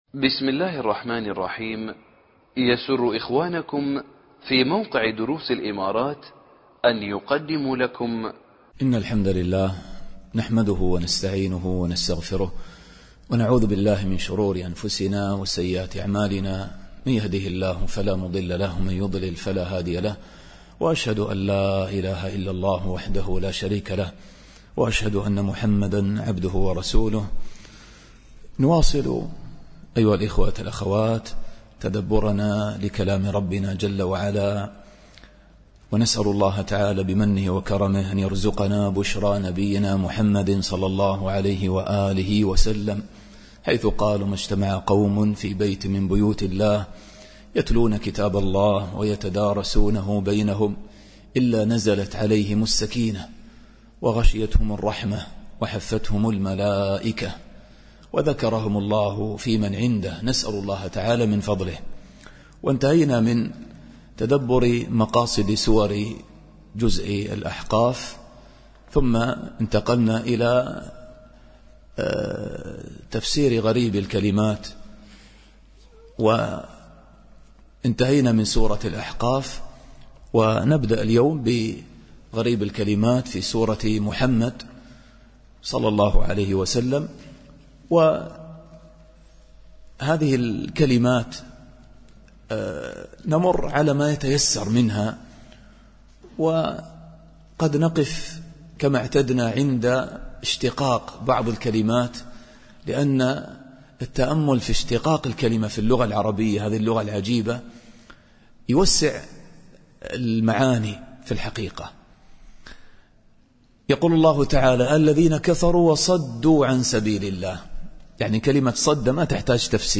الدرس الثامن والعشرون